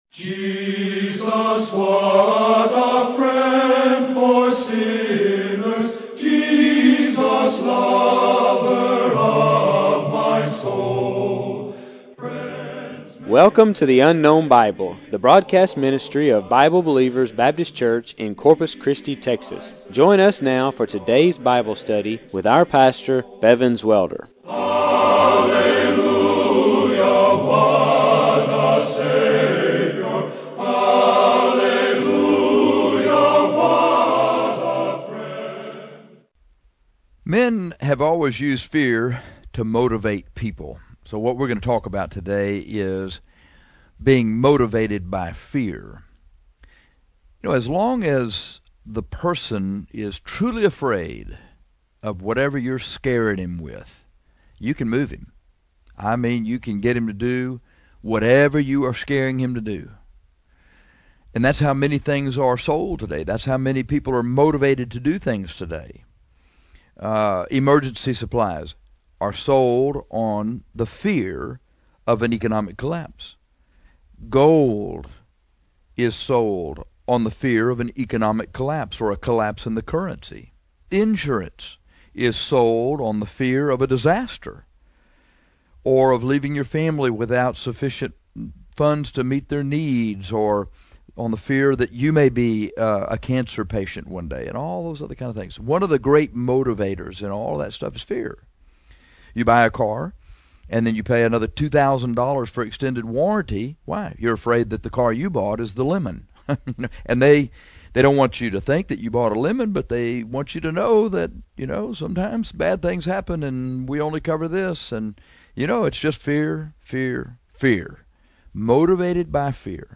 This lesson is on being motivated by fear.